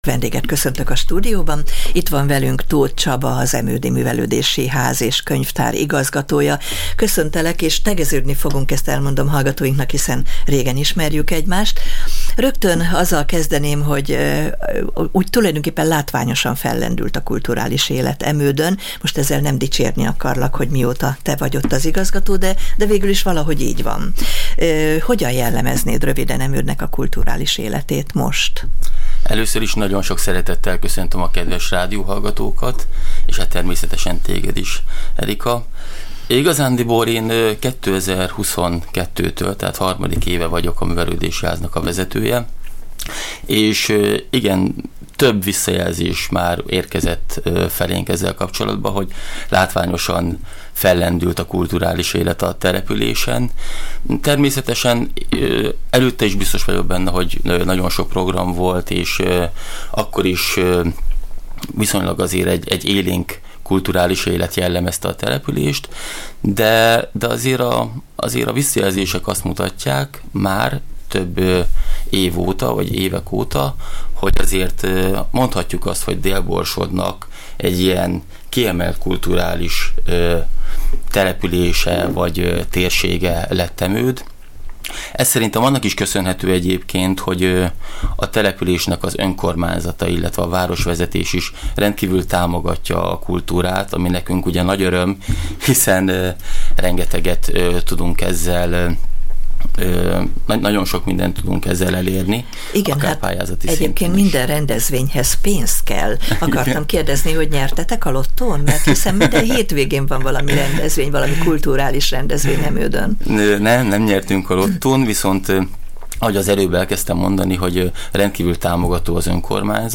Emőd település kultúrális élete az utóbbi 2-3 évben megváltozott. A Nyári esték sorozat , és a kiemelt rendezvények, fesztiválok a környékről is sok érdeklődőt vonzanak. Augusztus 20-án testvér településükről Parajdról lesznek küldöttek, akiknek pénzt gyüjtöttek, hogy segitsék az ott élőket, a természeti katasztrófa utáni állapotban. A Csillagpont Rádió vendége volt